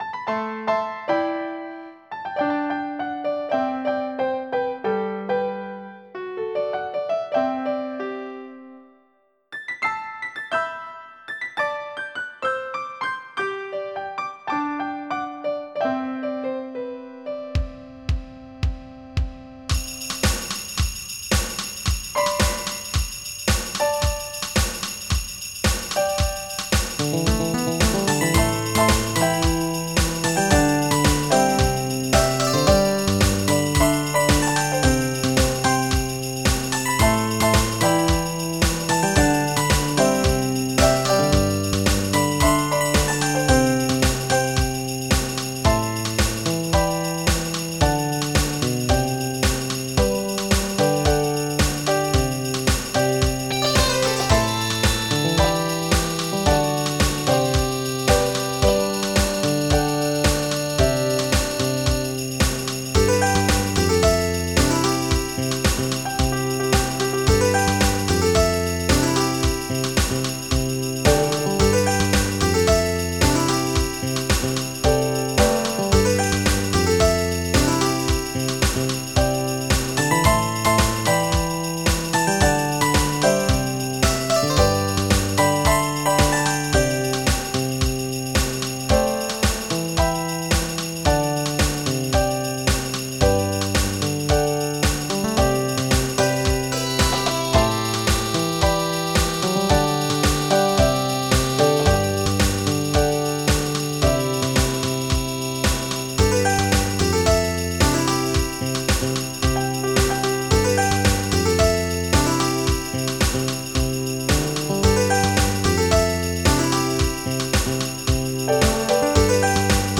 KARAOKE
MIDI Music File
Type General MIDI